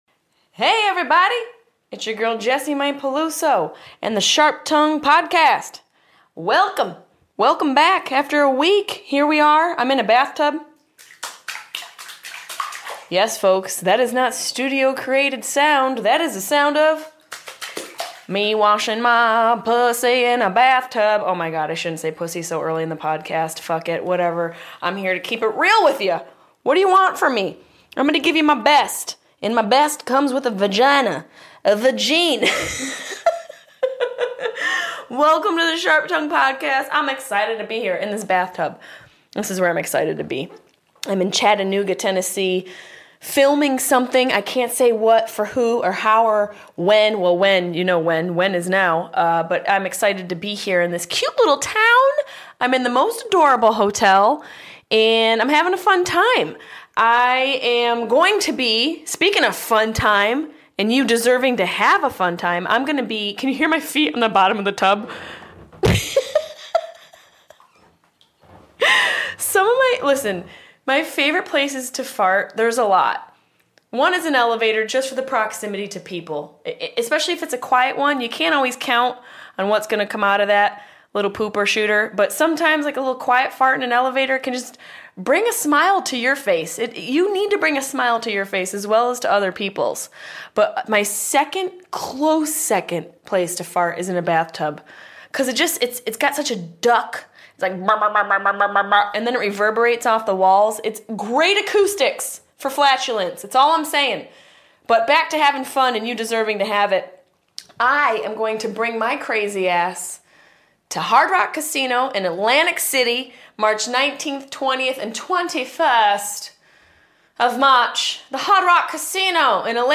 Funny woman, actor and North Carolina native, Fortune Feimster graces us in this real, revealing and raw episode of the SharpTongue Podcast. We discuss how she got hired for Chelsea Lately, being followed by paparazzi, how to age gracefully, and the importance of reaching out to a friend in need.